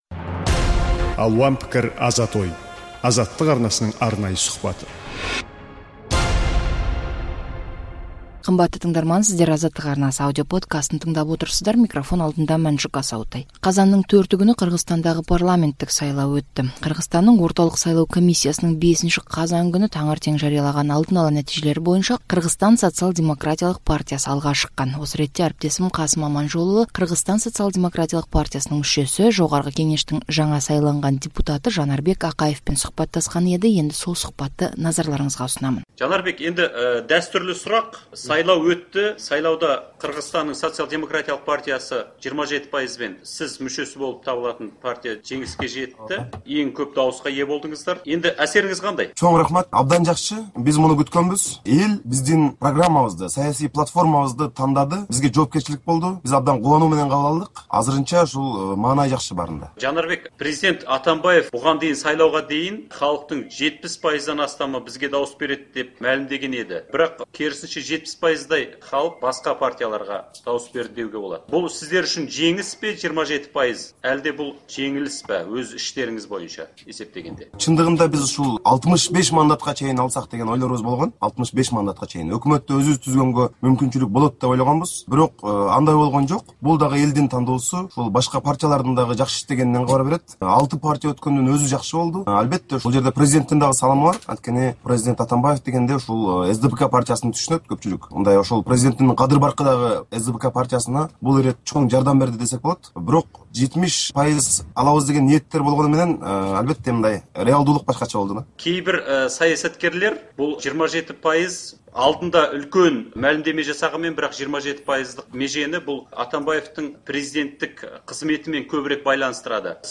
Қырғызстанда өткен сайлауда озып шыққан Социал-демократиялық партияның депутаттық мандаттан үміткер мүшесі Жанарбек Ақаевтың Азаттыққа сұхбаты.